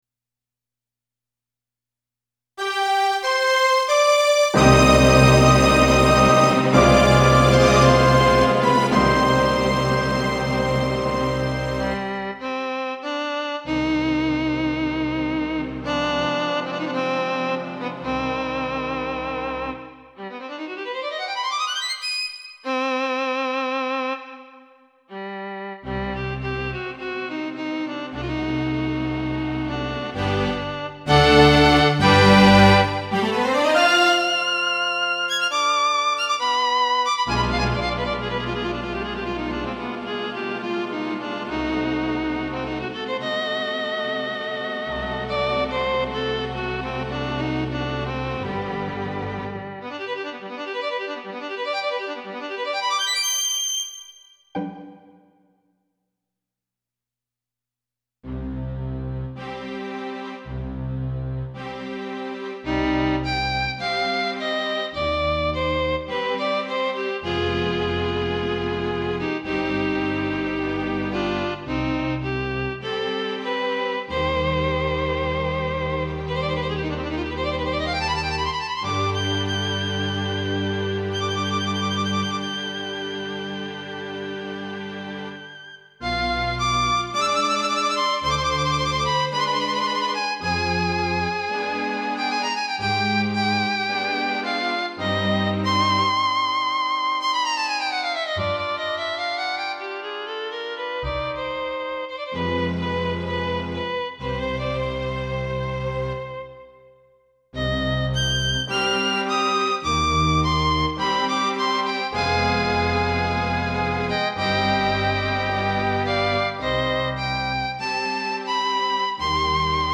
サラサーテ作曲『チゴイネルワイゼン』XG音源用